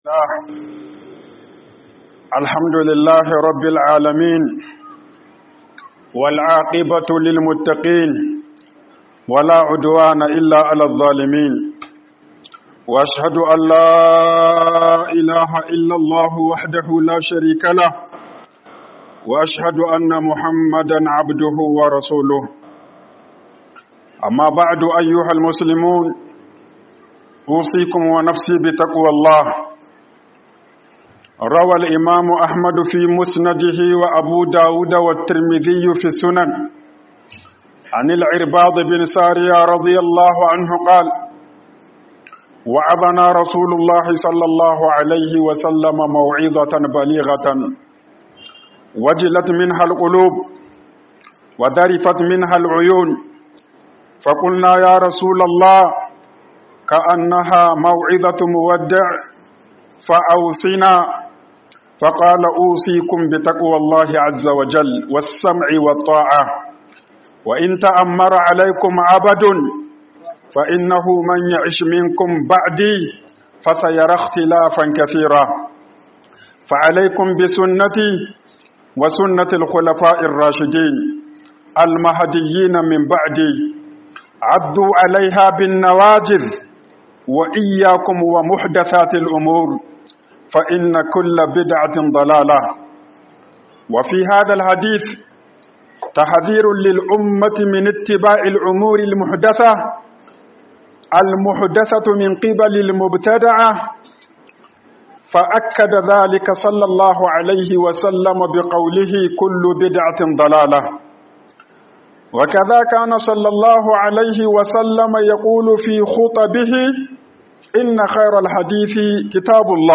HUƊUBOBIN JUMA'A